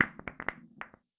Ball Impact Multiple Distant.wav